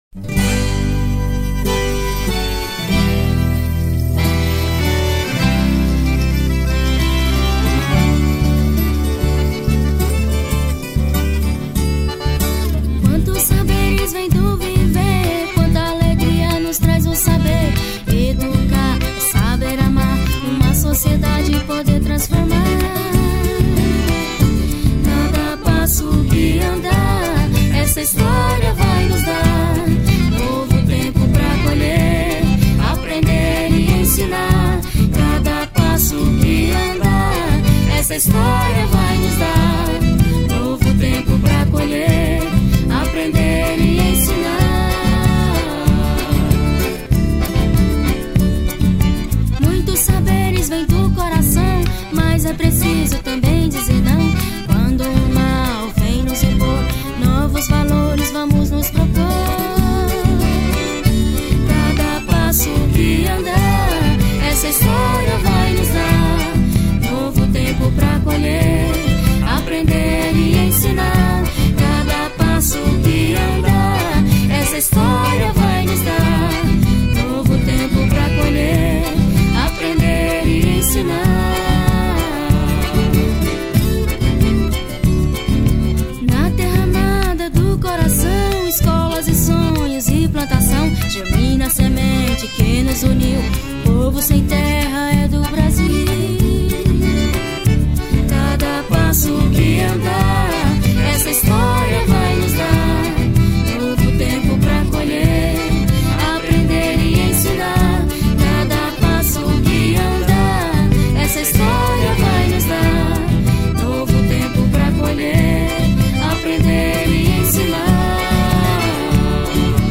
03:53:00   Ciranda